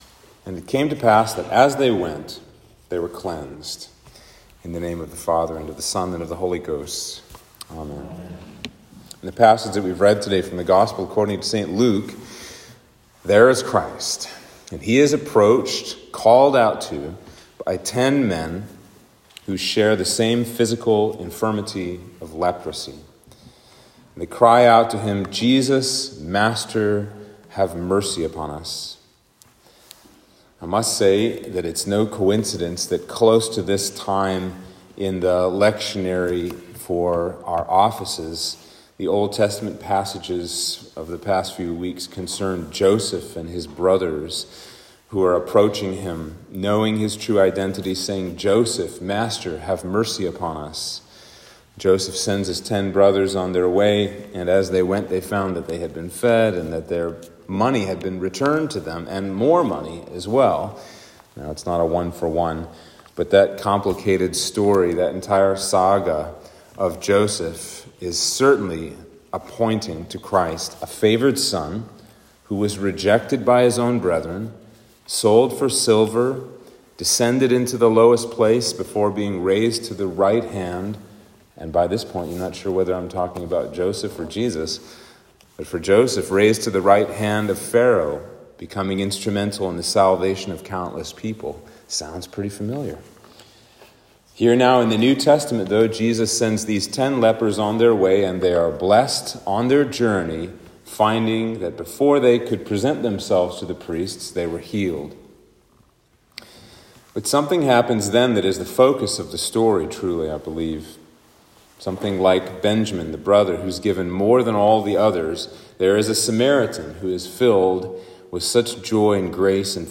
Sermon for Trinity 14